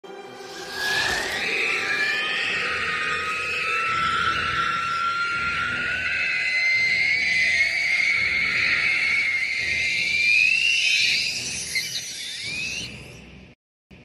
Nazgul scream long
witch-king-screech-compressed.mp3